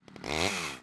WULA_MW_ChainSword_Hit.wav